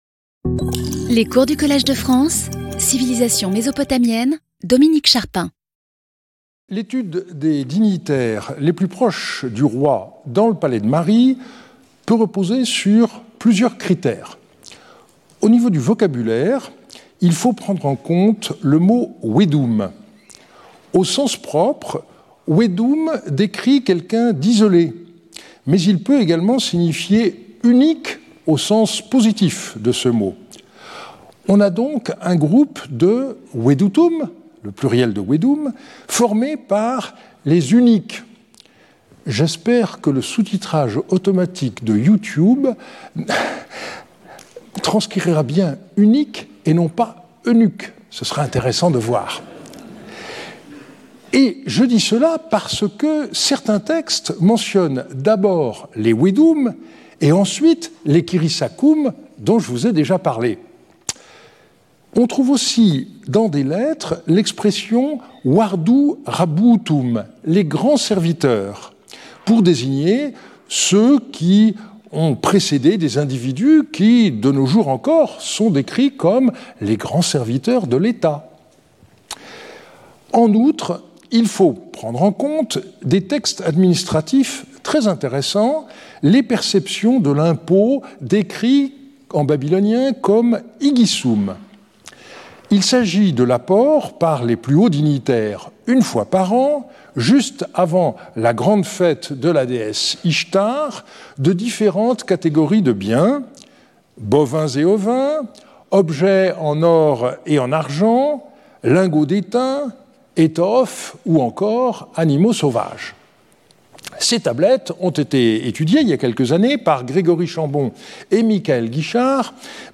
Heureusement, tel n'est pas le cas des « ministres de l'économie » ( šandabakkum ), successivement Yasim-Sumu et Ṣidqi-epuh : leur correspondance montre le soin avec lequel ils géraient les différents domaines royaux, dans la vallée de l'Euphrate et celle du Habur. Intervenant(s) Dominique Charpin Professeur du Collège de France Événements Précédent Cours 13 Jan 2025 11:00 à 12:00 Dominique Charpin Introduction : Hammu-rabi, destructeur de Mari et Parrot, inventeur de Mari …